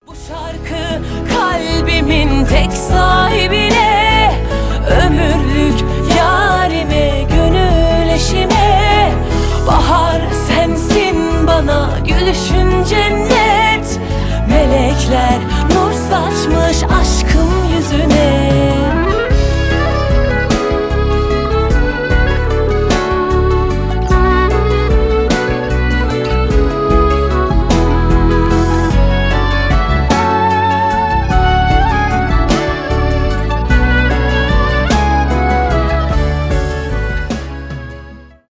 Турецкие рингтоны , поп , женский голос